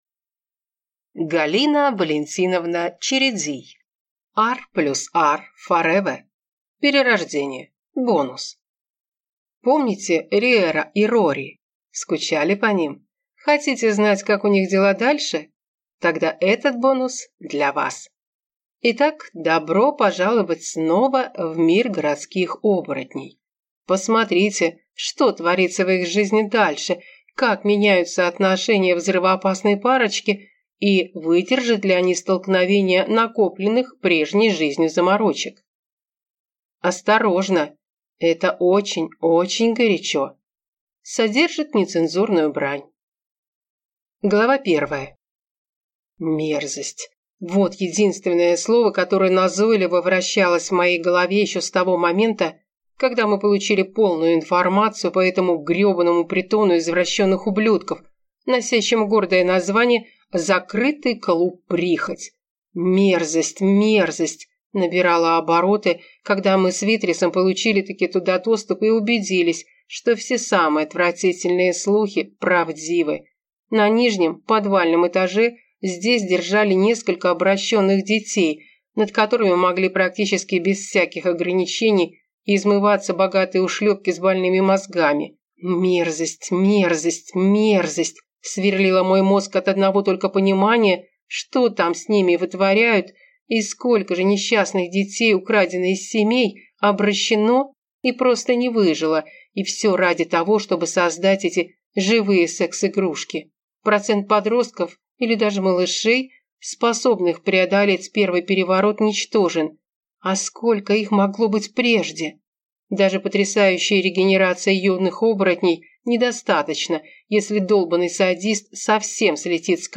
Аудиокнига R+R FOREVER (Перерождение. Бонус) | Библиотека аудиокниг